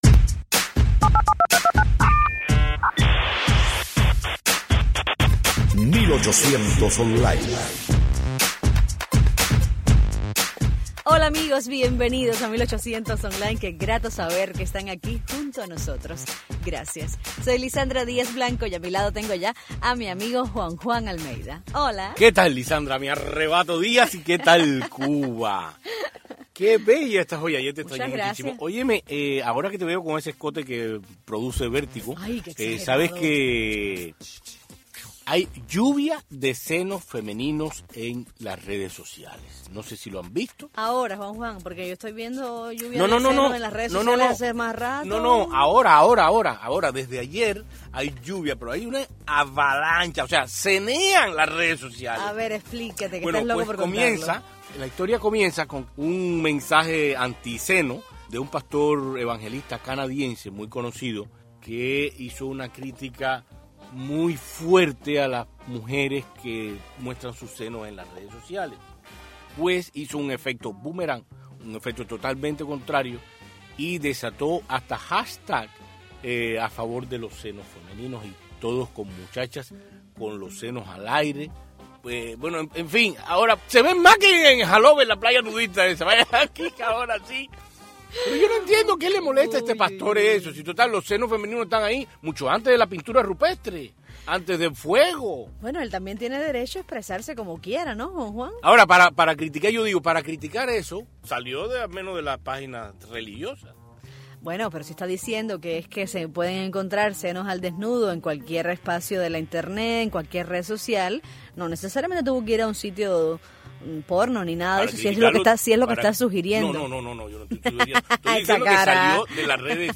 Entrevista a la cantante cubana Daymé Arocena sobre su nuevo álbum Al-Kemi